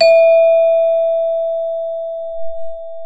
CELESTE E3.wav